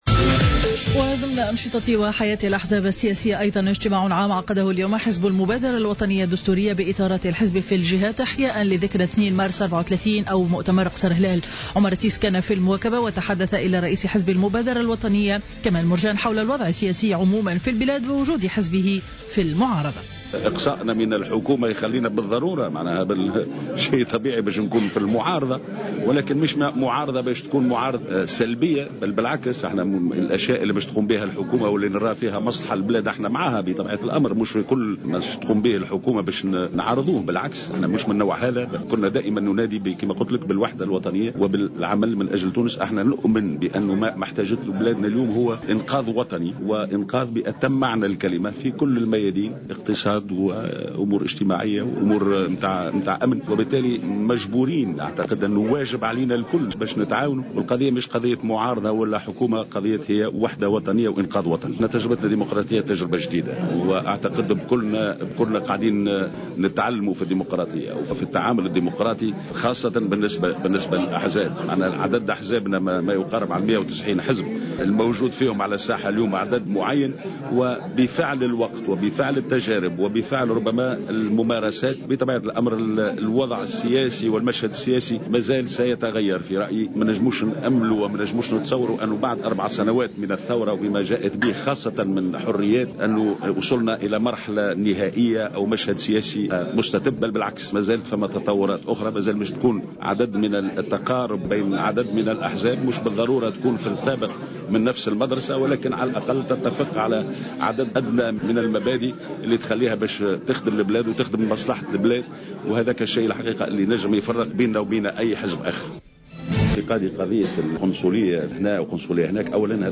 وتحدث رئيس حزب المبادرة الوطنية كمال مرجان إلى جوهرة أف أم حول الوضع السياسي في البلاد وعن الموقف التونسي تجاه ما يحدث في ليبيا .